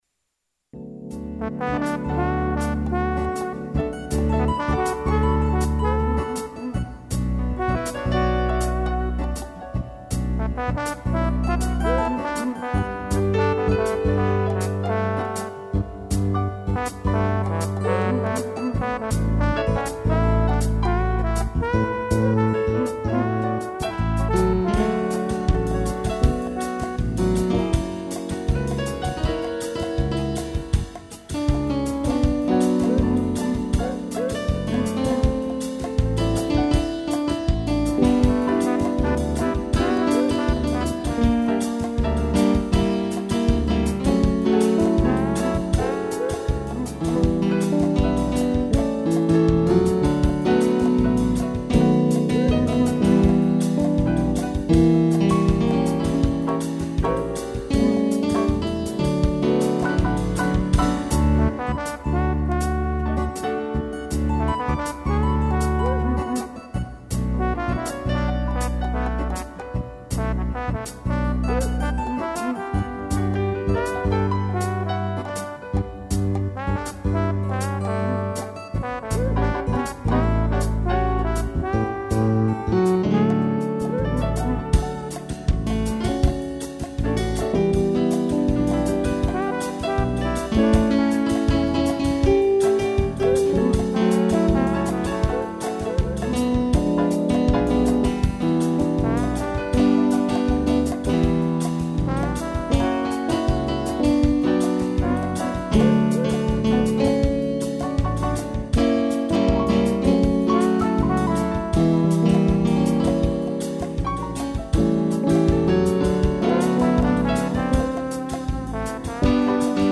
piano, trombone, cuíca e tamborim